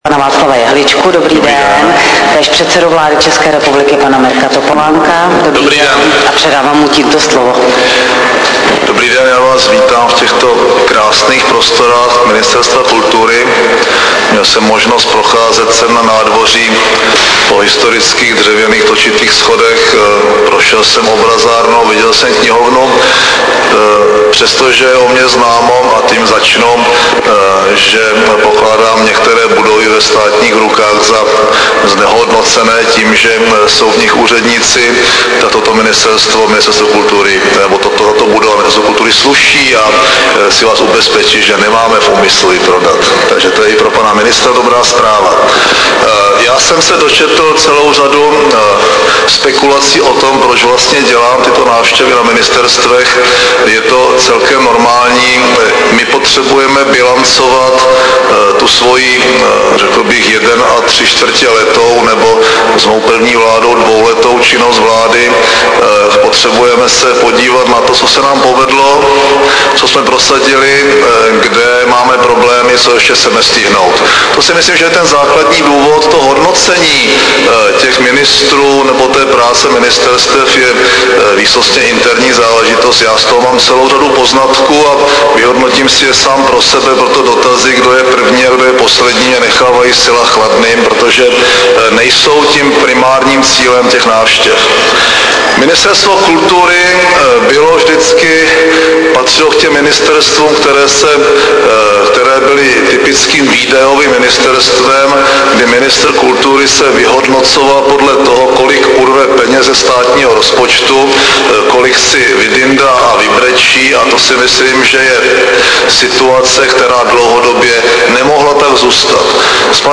Tisková konference premiéra Mirka Topolánka a ministra kultury Václava Jehličky 28. srpna 2008